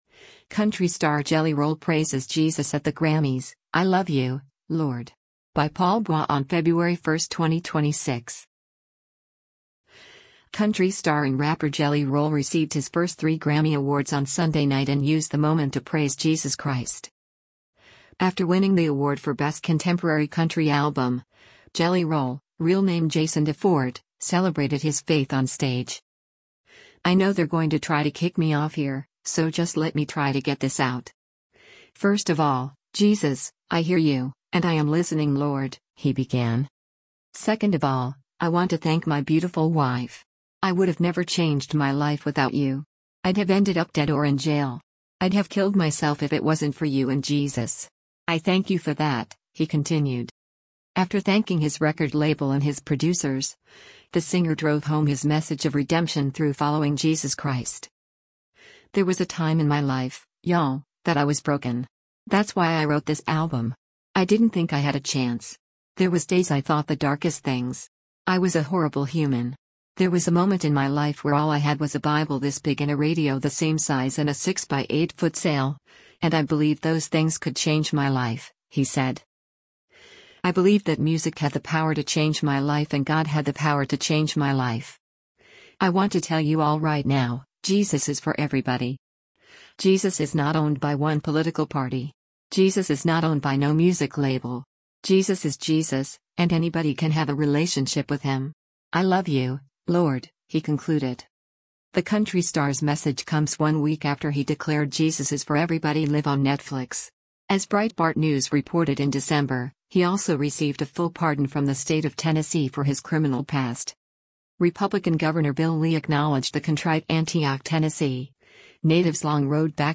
After winning the award for Best Contemporary Country Album, Jelly Roll, real name Jason DeFord, celebrated his faith on stage.